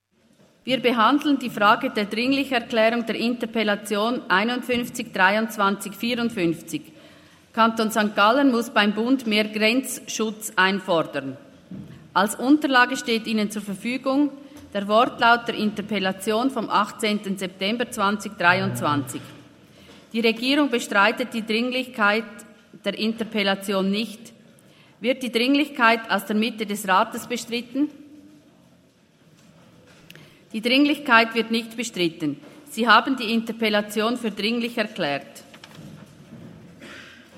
19.9.2023Wortmeldung
Session des Kantonsrates vom 18. bis 20. September 2023, Herbstsession